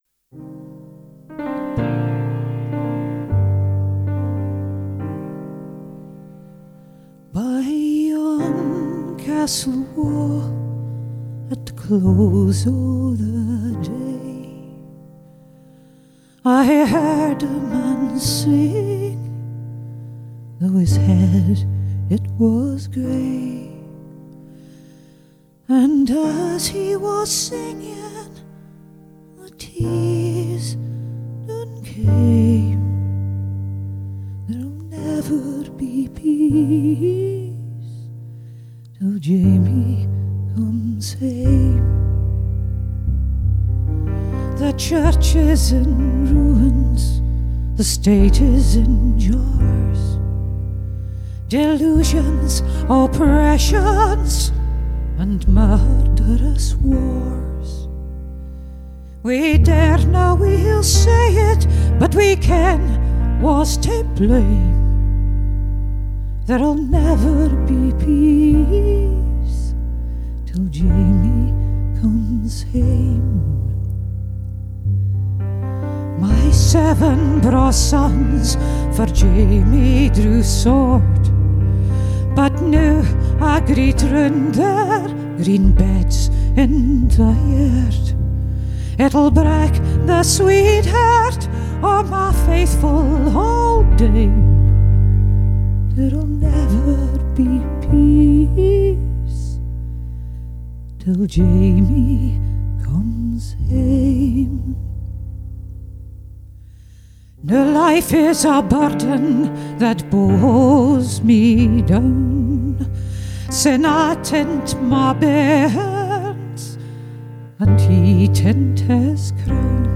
un album fait maison aux sonorités folk
Un beau voyage dans ces contrées musicales écossaises.